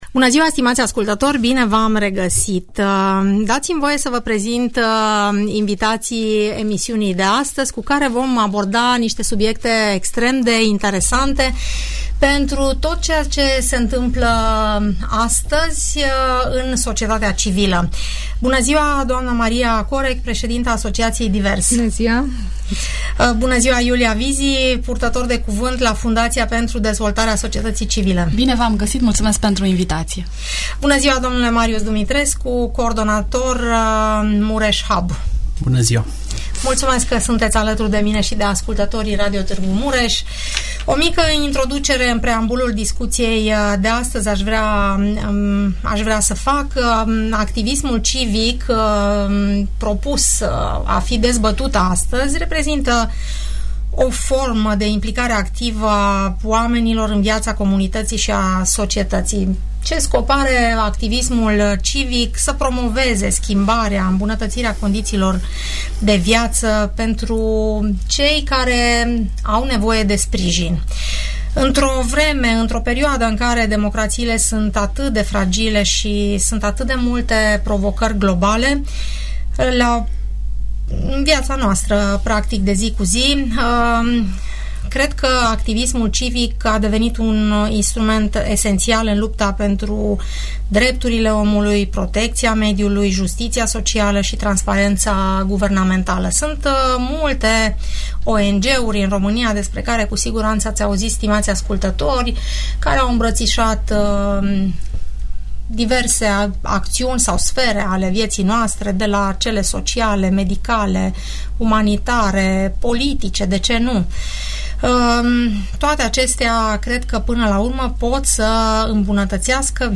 De ce este important civismul, cu ce provocări se confruntă cei care aleg să fie vocea schimbării sau ce impact are digitalizarea asupra activității ONG-urilor, discutăm la Radio Tg. Mureș în emisiunea "Părerea ta".